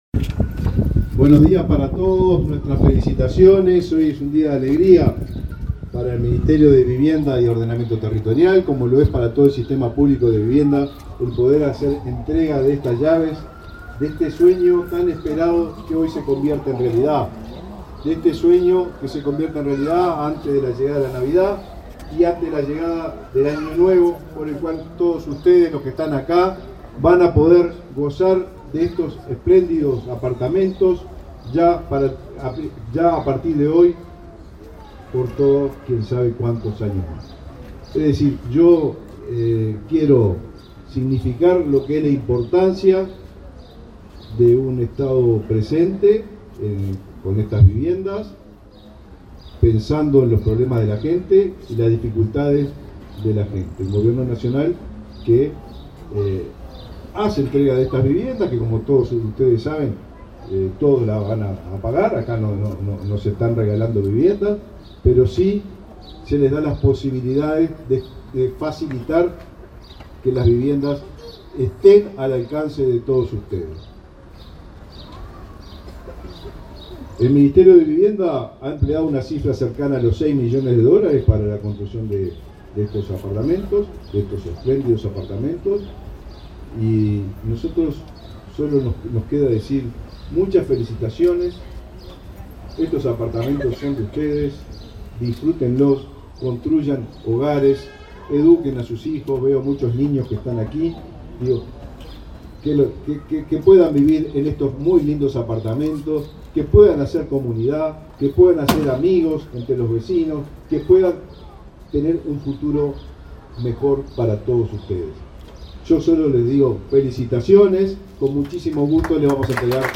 Palabras del ministro de Vivienda, Raúl Lozano
Este martes 12, el ministro de Vivienda, Raúl Lozano, participó en la entrega de 70 viviendas en las modalidades de compra y alquiler con opción de